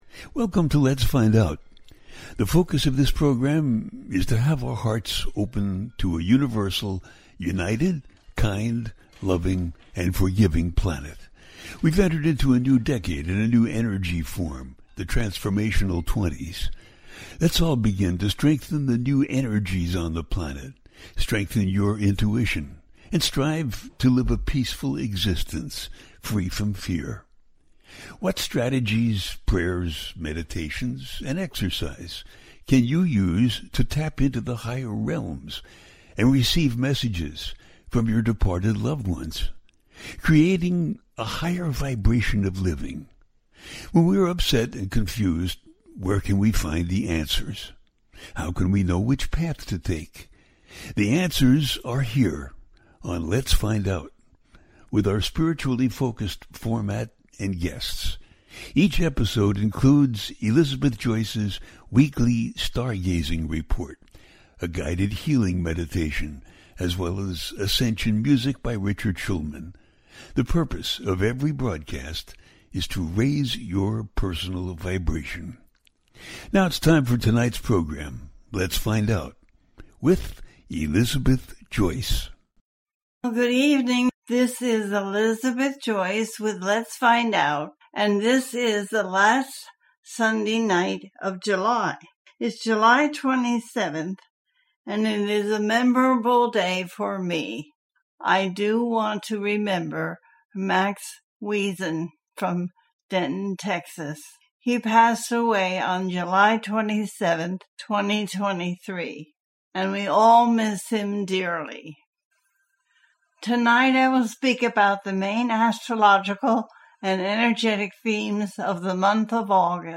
What's Coming In August 2025 and Beyond - A teaching show